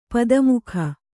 ♪ pada mukha